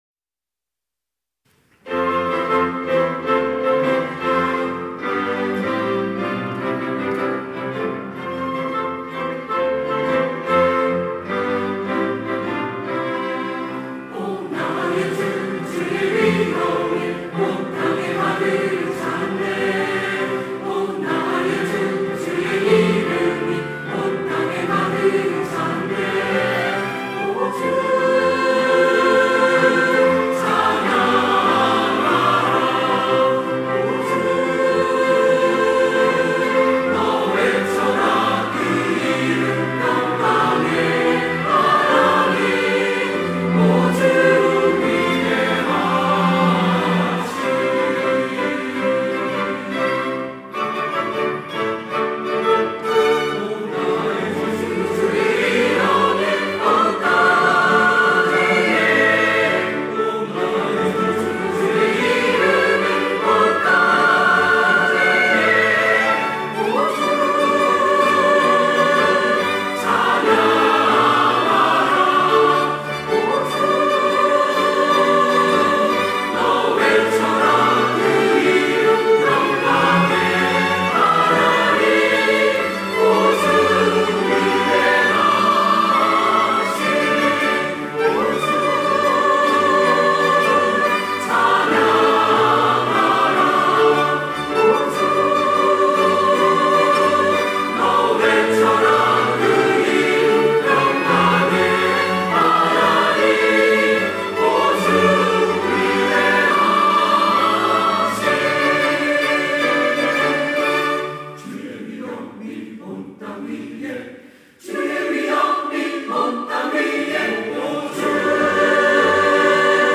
호산나(주일3부) - 주의 위엄이 온 땅 위에
찬양대